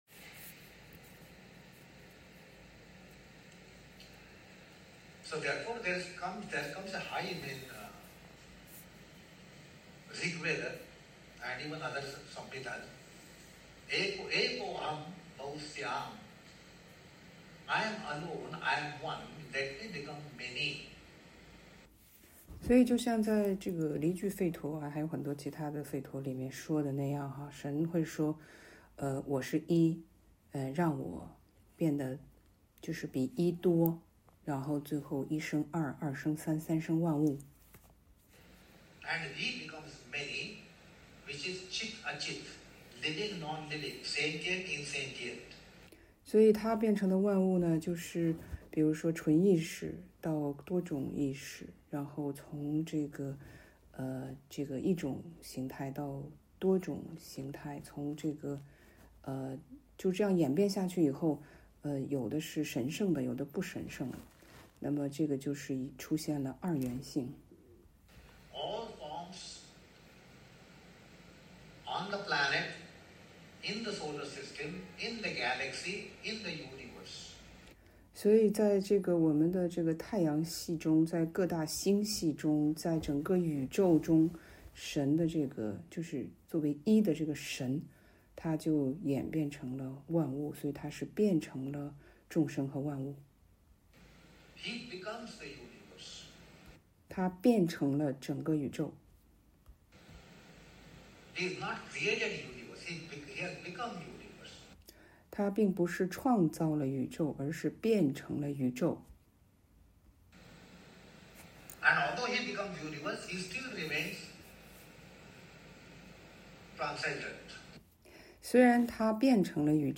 帕坦伽利紀念日講話（二）
帕坦伽利紀念日講話02.mp3